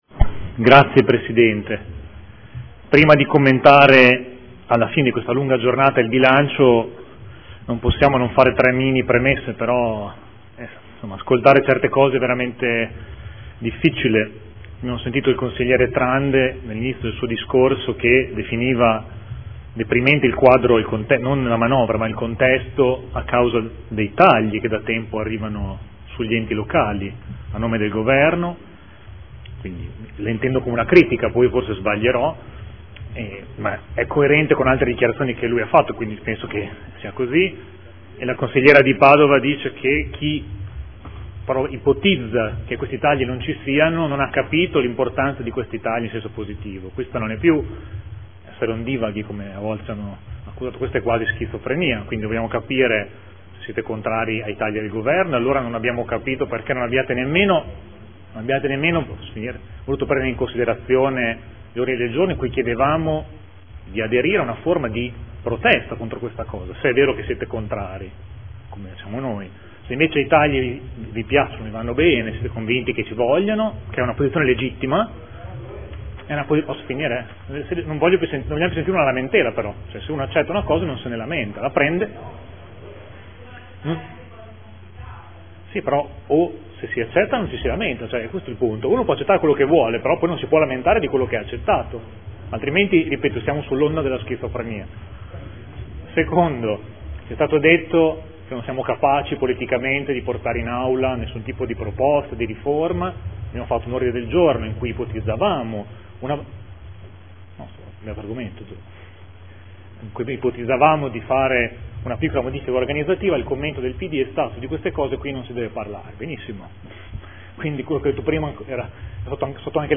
Seduta del 05/03/2015 Dichiarazione di voto sul Bilancio e sulle delibere accessorie